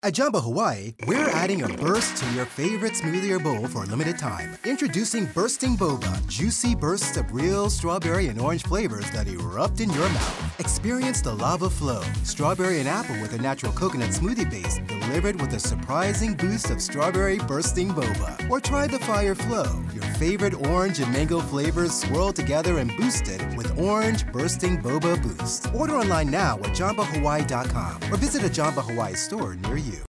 Male
Adult (30-50), Older Sound (50+)
Radio Commercials